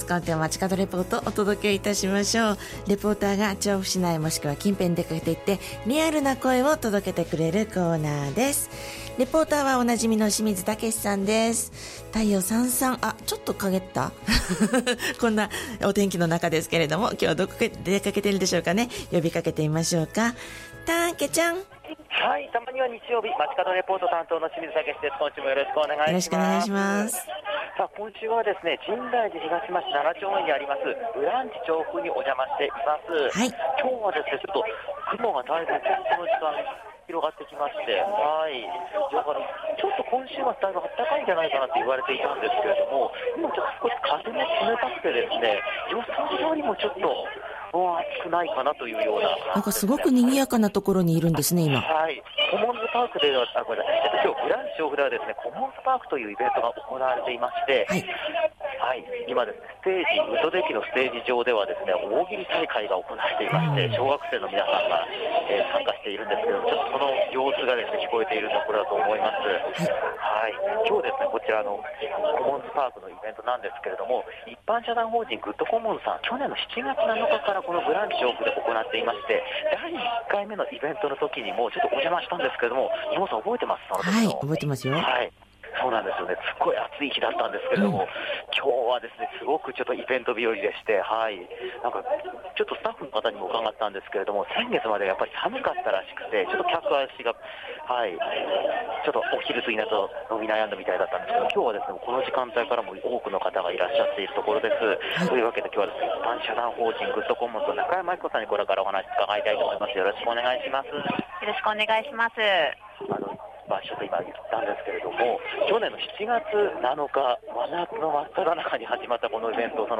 少し雲が出てきた下からお届けした本日の街角レポートは、 ブランチ調布で開催中の「コモンズパーク」からのレポートです！！